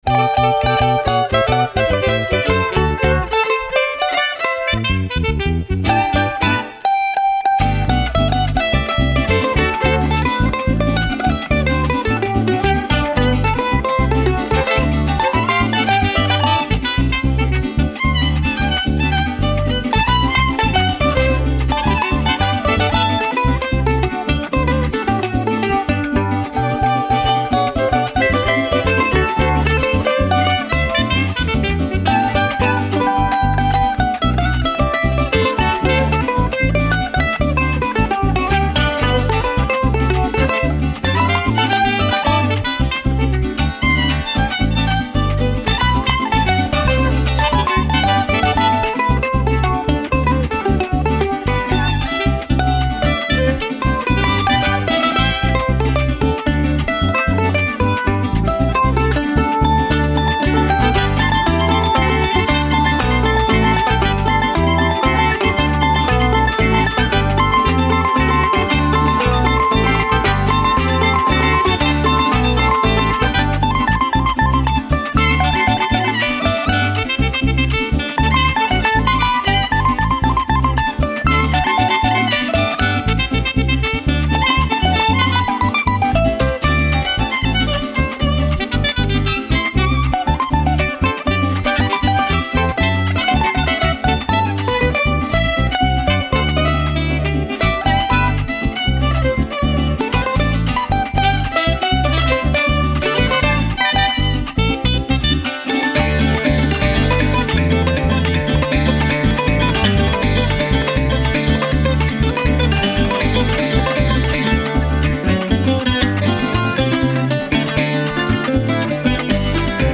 La música de América Latina